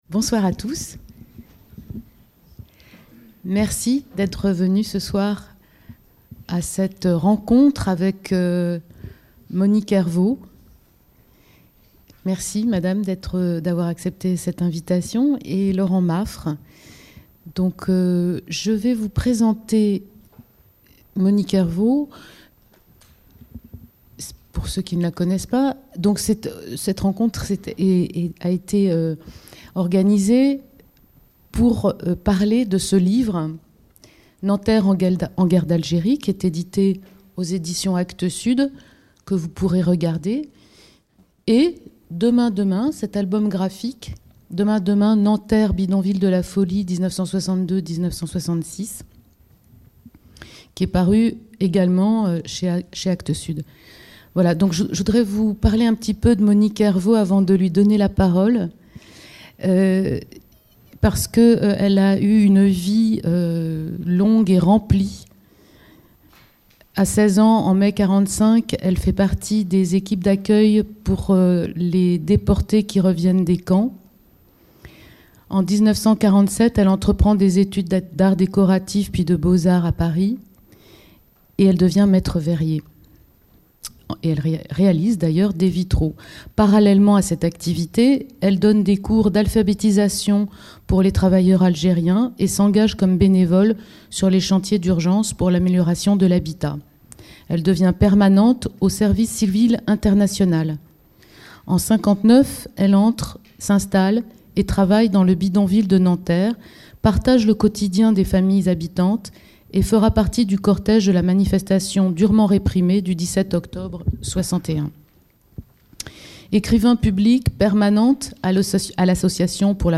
Rencontre avec les auteurs de la bande dessinée publiée chez Actes Sud.
conférence enregistrée au Salon de lecture Jacques Kerchache le 21 mars 2013